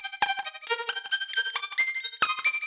Index of /musicradar/shimmer-and-sparkle-samples/90bpm
SaS_Arp03_90-C.wav